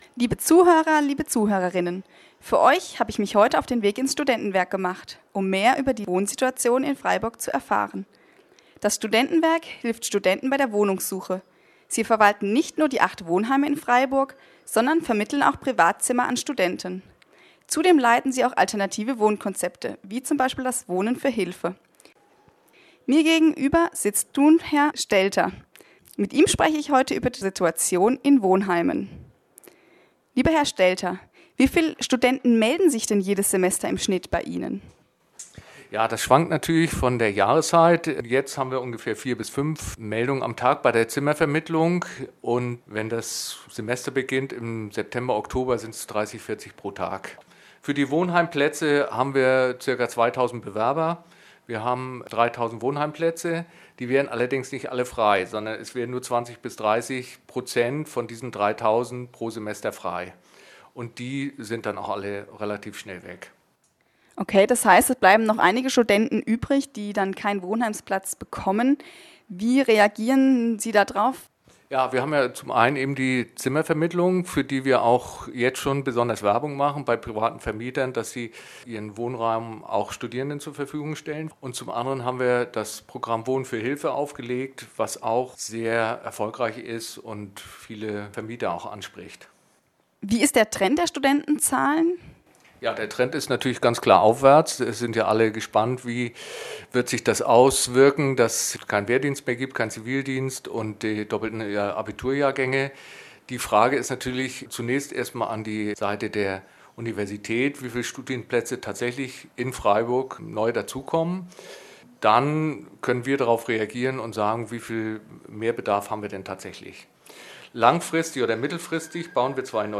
Dieser Eintrag wurde veröffentlicht unter Interview Gesellschaft & Soziales On Air und verschlagwortet mit Medien Studium deutsch am von